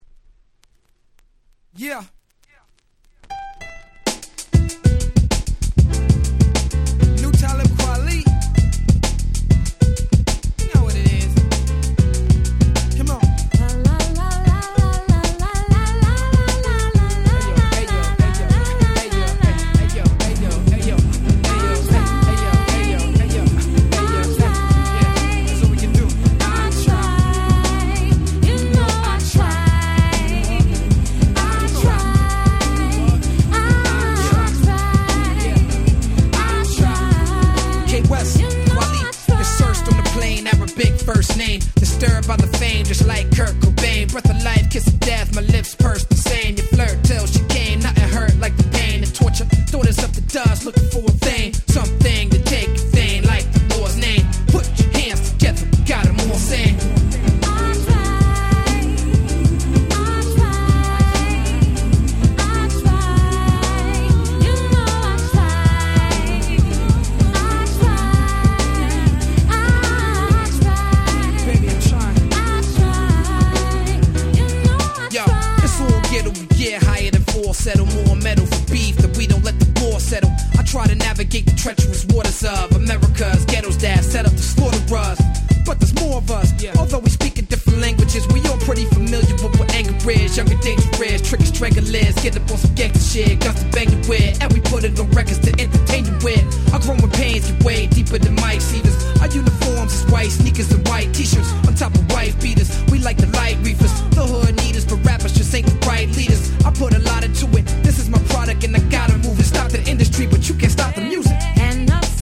04' Very Nice Hip Hop !!